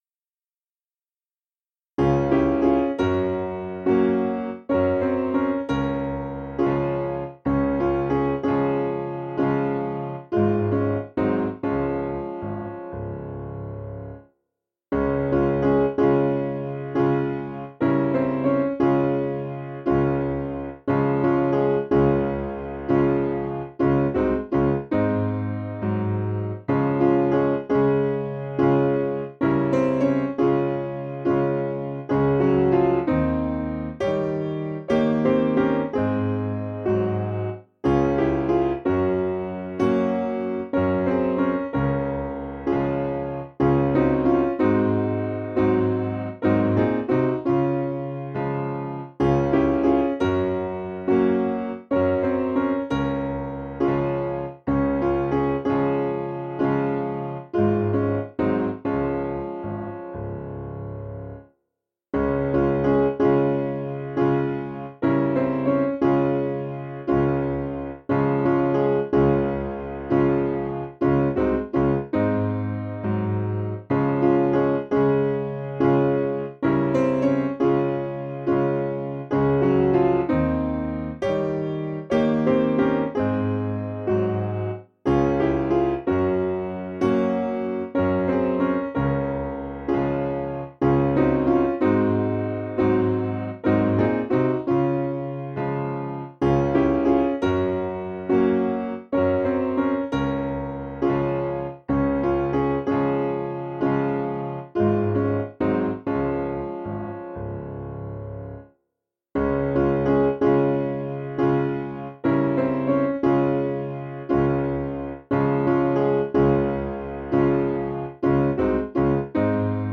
Information about the hymn tune [O to be like Thee! Blessed Redeemer].
Piano/Organ
Key: D Major